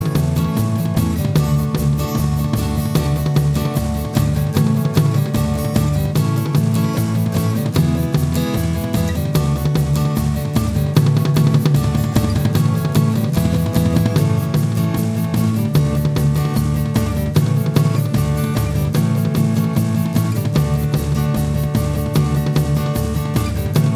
no Backing Vocals Rock 'n' Roll 2:09 Buy £1.50